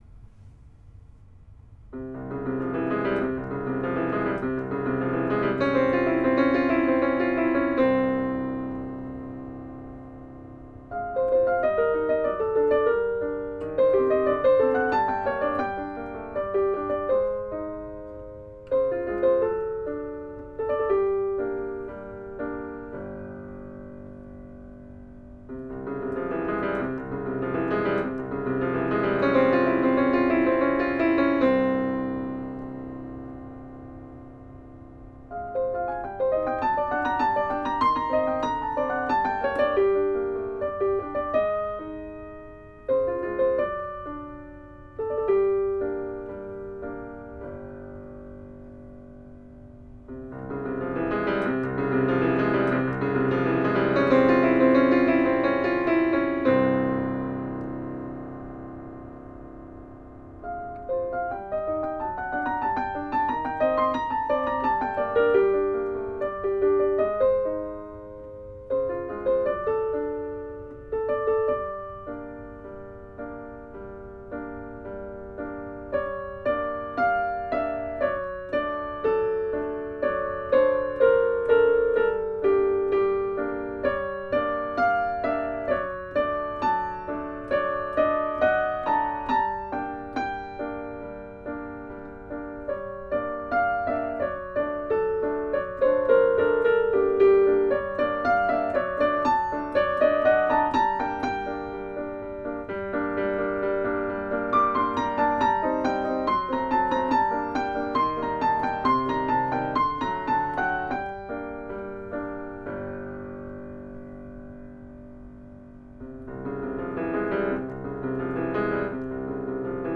Please keep in mind, I recorded these on my electric piano using a hand-held digital recorder.  Not exactly a professional job!